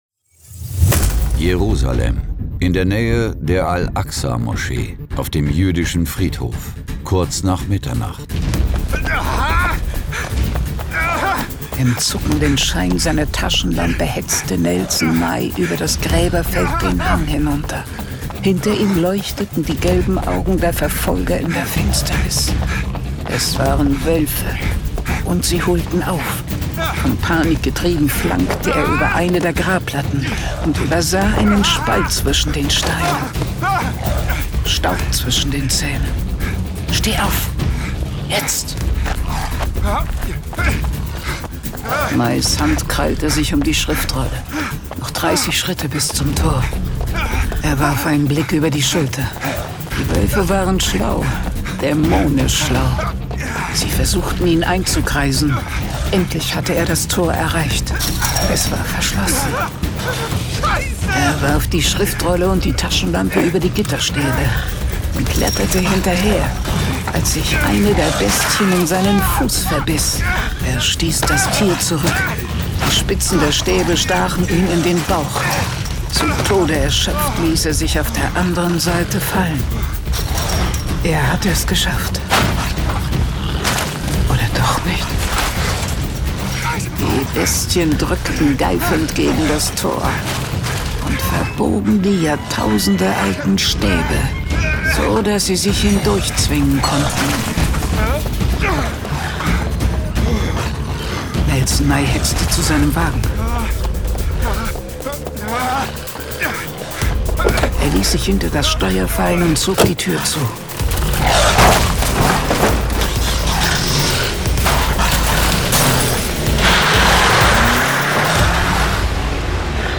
John Sinclair - Folge 172 Das Erbe der Templer. Hörspiel.